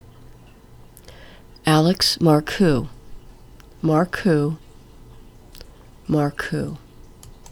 Mar-coo